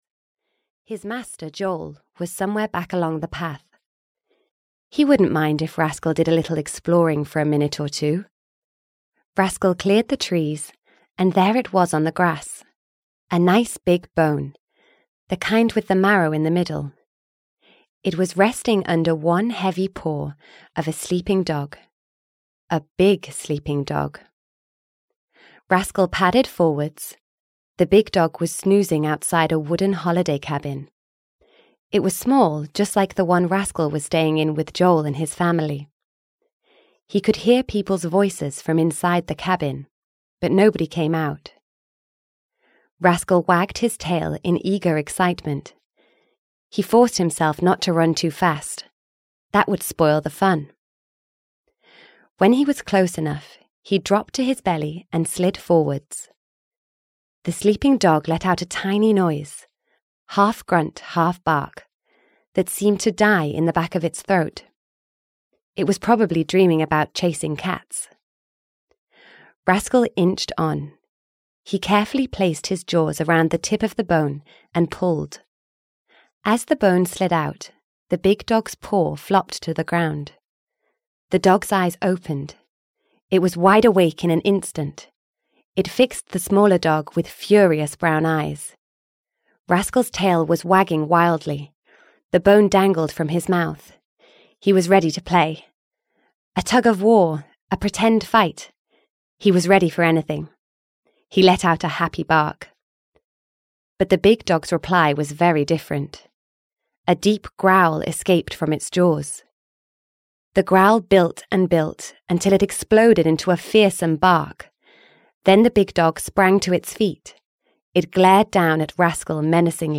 Audio knihaRascal 1 - Lost in the Caves (EN)
Ukázka z knihy